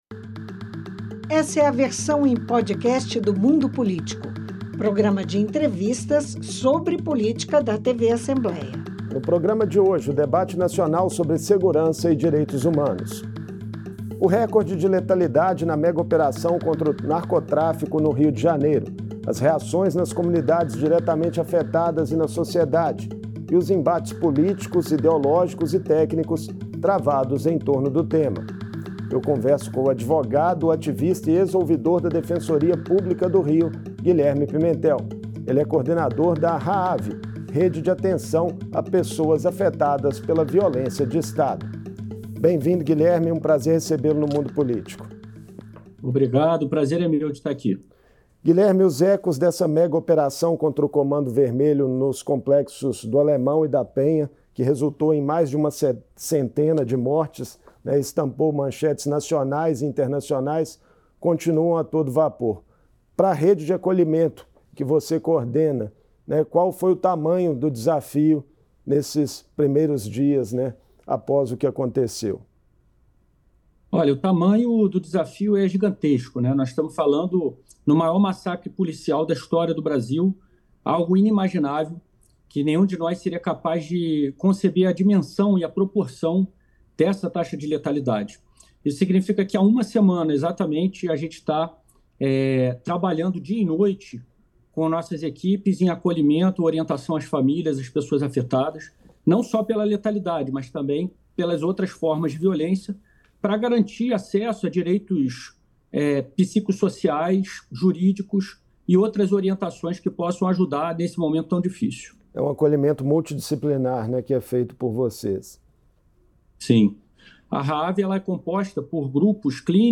Uma semana após a megaoperação das polícias nos complexos do Alemão e da Penha que deixou 117 suspeitos e 4 policiais mortos, está colocado um debate que apoia e, de outro lado, condena a matança no Rio de Janeiro. Em entrevista